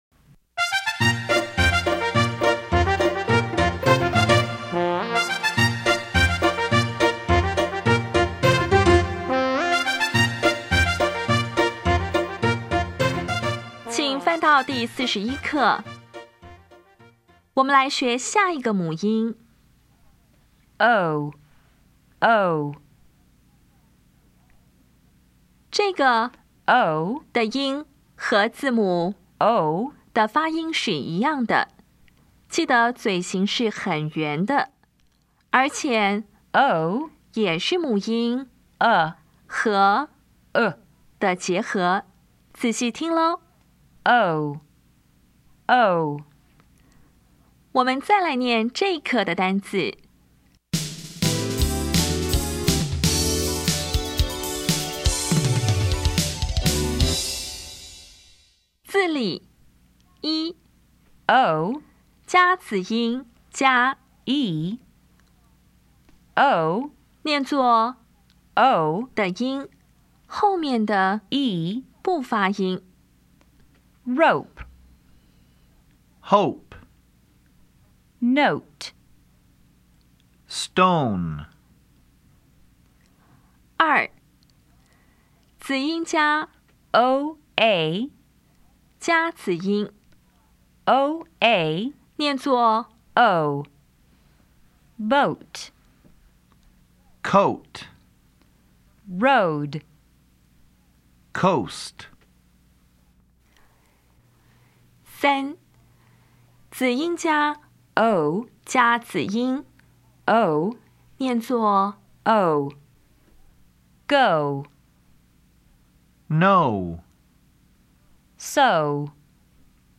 [o]
音标讲解第四十一课
比较 [aʊ][o]
比较 [ɔ] [o]
Listening Test 17